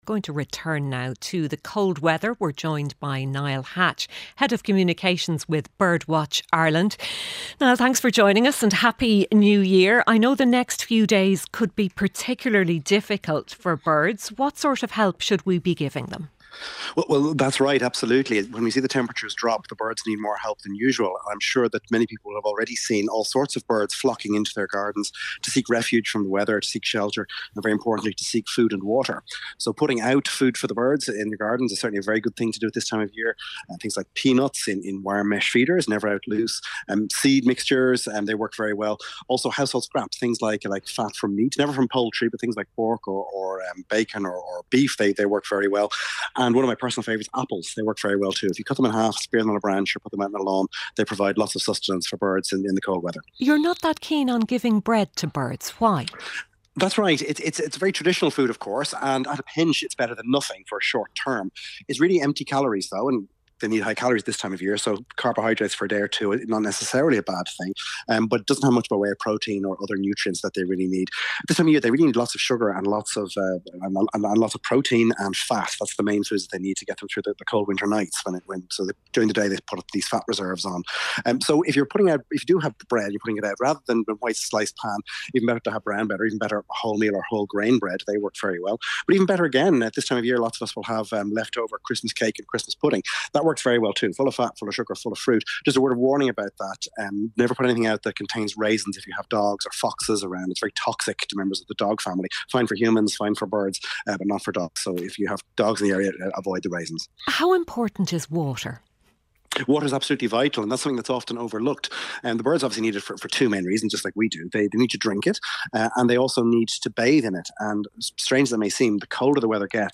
News, sport, business and interviews.
Listen live Monday to Friday at 1pm on RTÉ Radio 1.